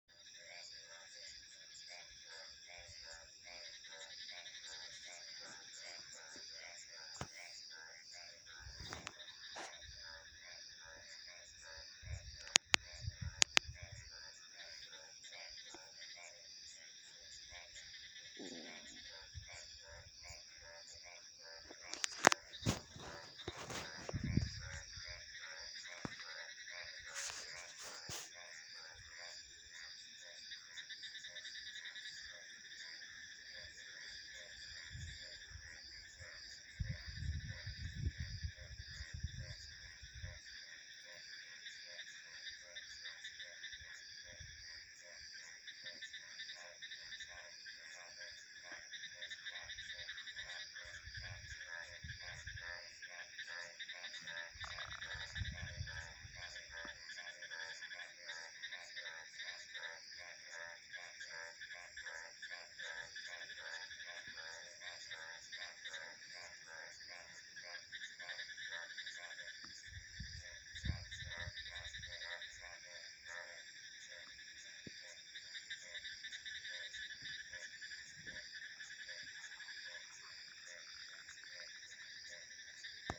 Download Free Night Sound Effects
Night